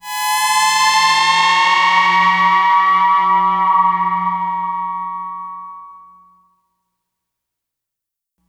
Moog Rise.wav